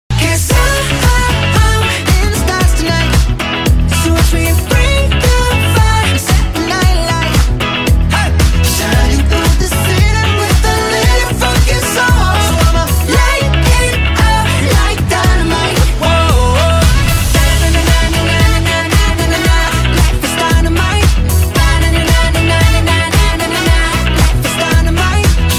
military band performance